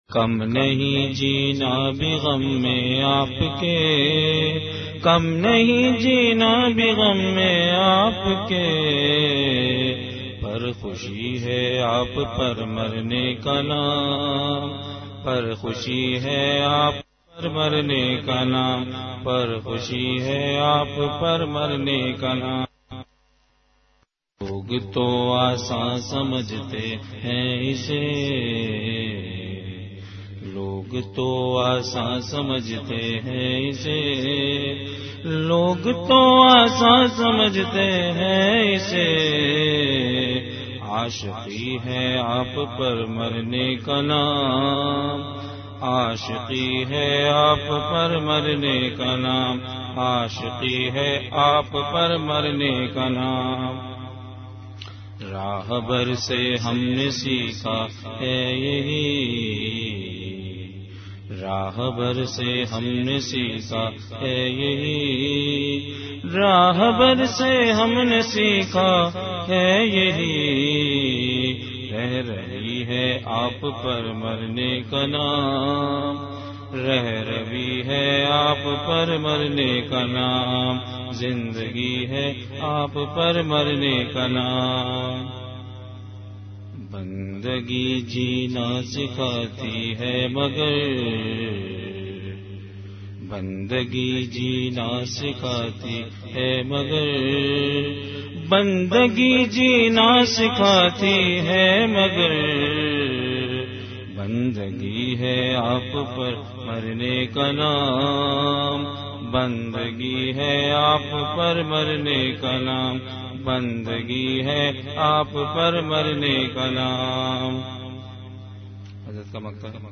An Islamic audio bayan